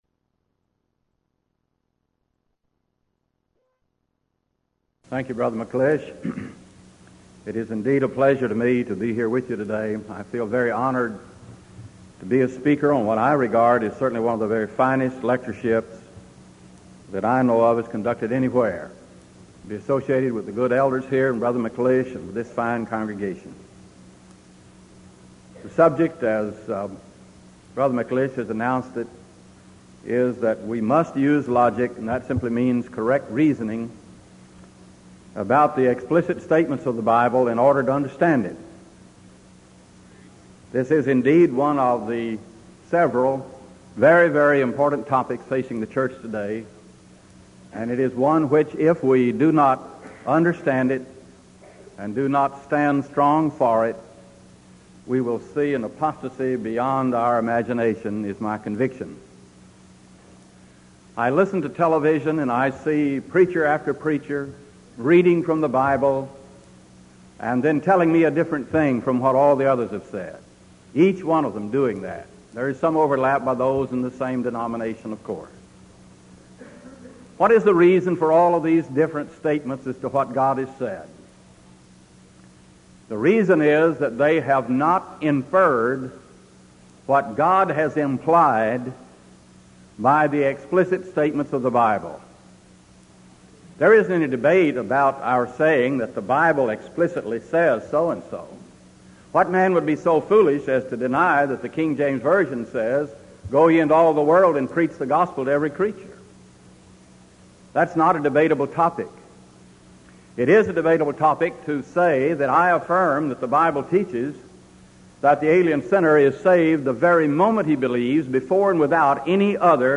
Title: Discussion Forum
Event: 1986 Denton Lectures Theme/Title: Studies in Galatians